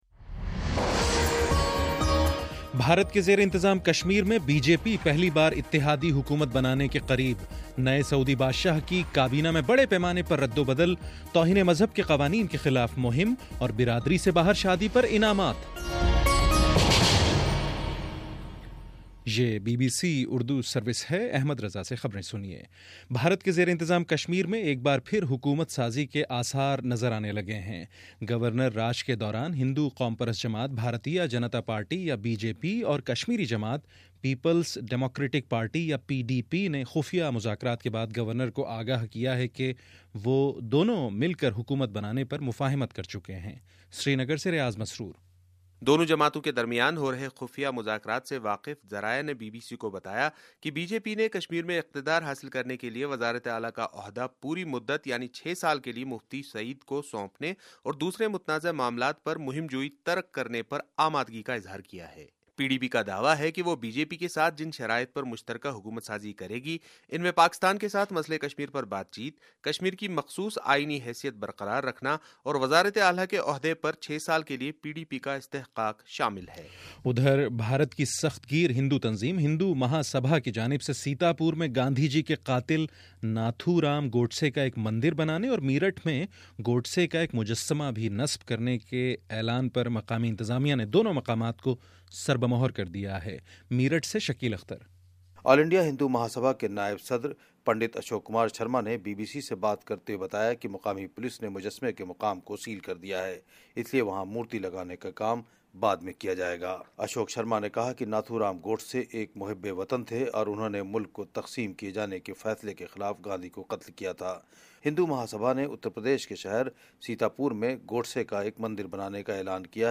جنوری 30: صبح نو بجے کا نیوز بُلیٹن